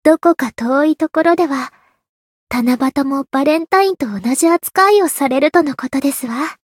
灵魂潮汐-安德莉亚-七夕（送礼语音）.ogg